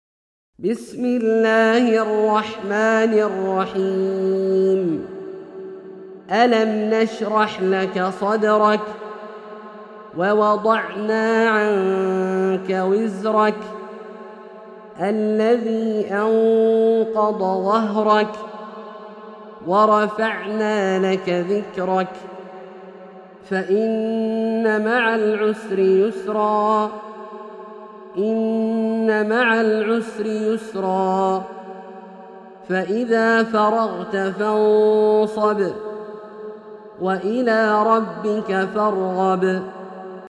سورة الشرح - برواية الدوري عن أبي عمرو البصري > مصحف برواية الدوري عن أبي عمرو البصري > المصحف - تلاوات عبدالله الجهني